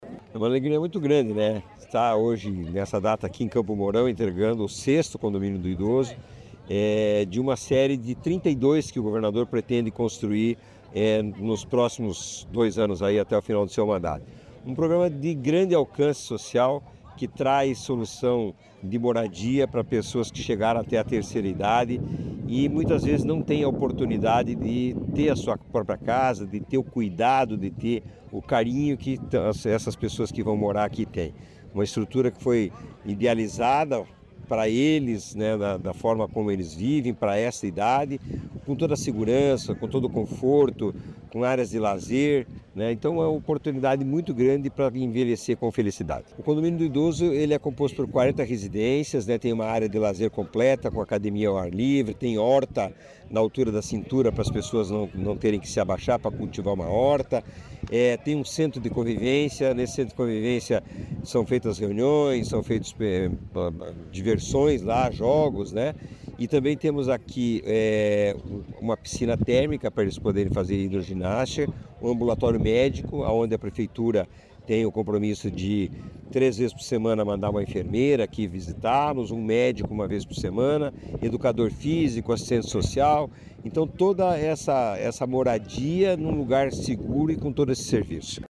Sonora do presidente da Cohapar, Jorge Lange, sobre a entrega do Condomínio do Idoso de Campo Mourão